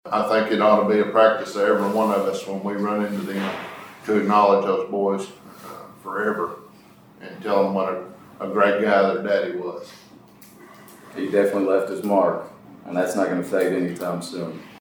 The Caldwell County Fiscal Court paused its Tuesday session to honor the late Jailer Willie Harper, who was remembered fondly for his dedication, financial stewardship, and ever-present smile following his courageous battle with cancer.